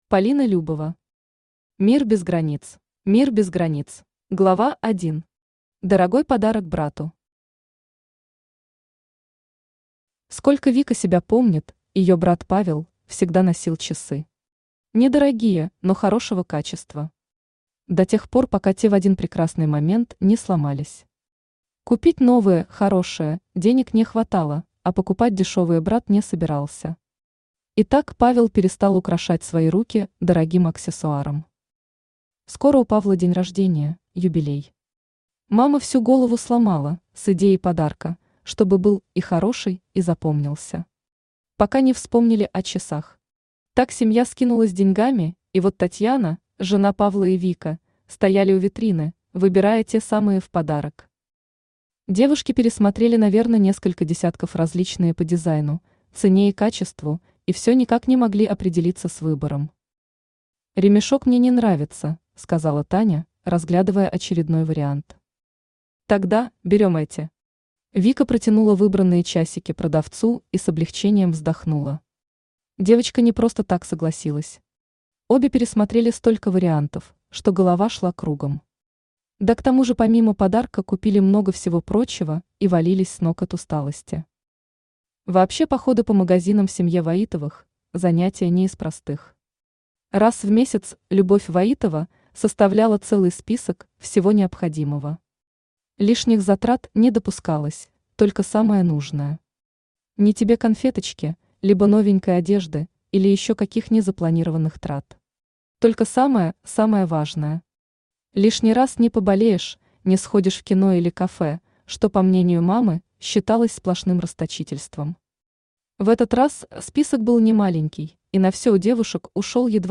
Аудиокнига Мир без границ | Библиотека аудиокниг
Aудиокнига Мир без границ Автор Полина Любова Читает аудиокнигу Авточтец ЛитРес.